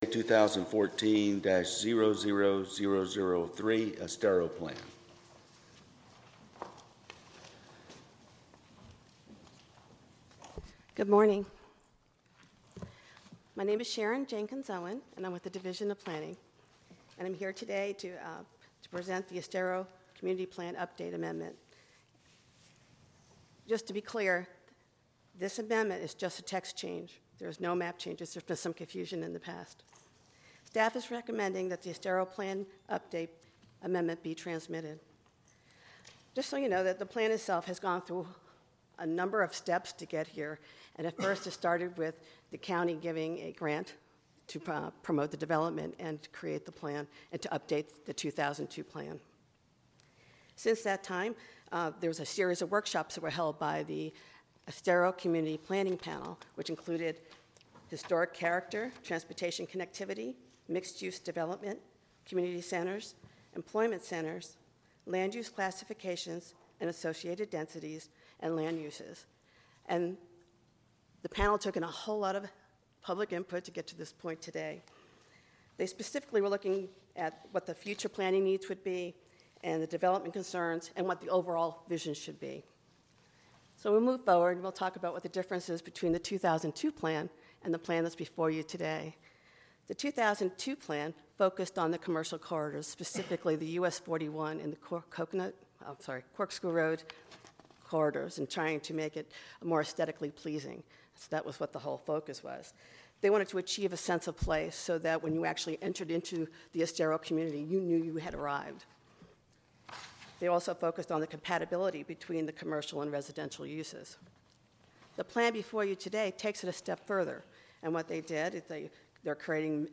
ESTERO COMMUNITY PLAN UPDATE AUDIO LPA MEETING 05-19-14